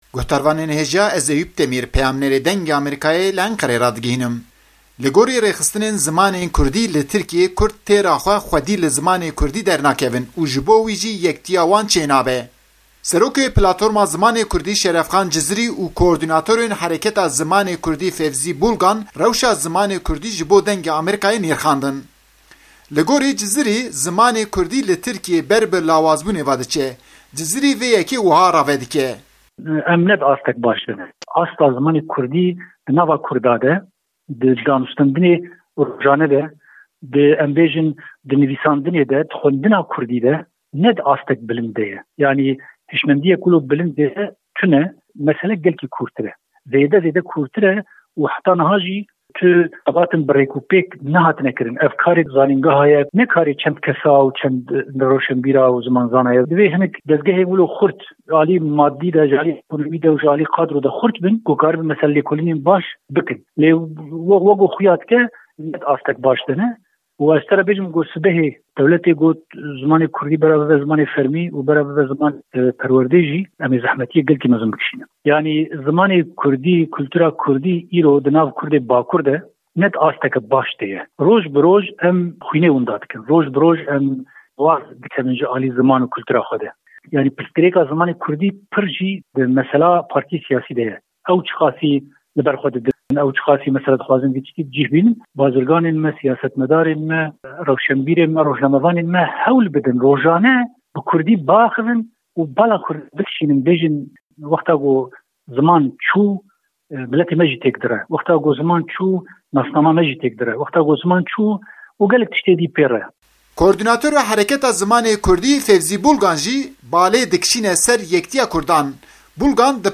raporta Enqerê